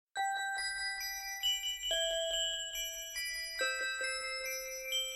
Categoria Natale